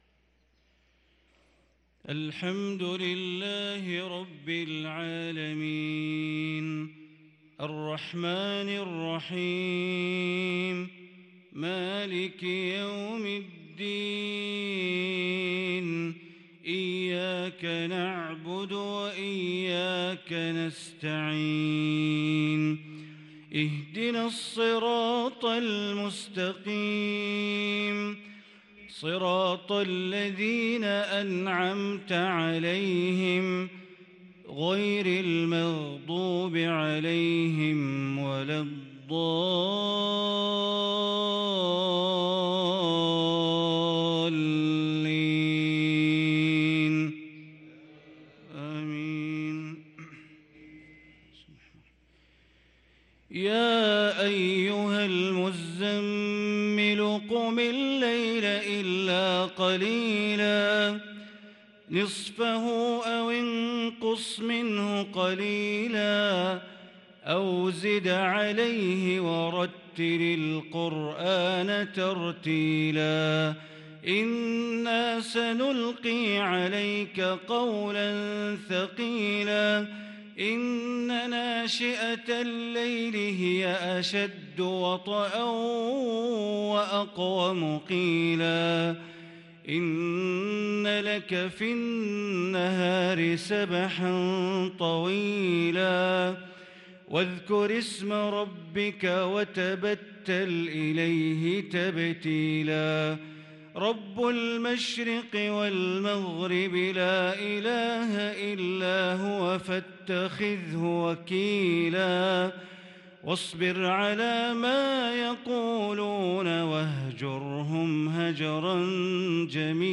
صلاة العشاء للقارئ بندر بليلة 20 ربيع الأول 1444 هـ